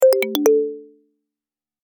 Index of /phonetones/unzipped/Alcatel/OT-3088X/notifications
notifier_Complete.ogg